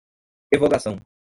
Pronunciado como (IPA)
/ʁe.vo.ɡaˈsɐ̃w̃/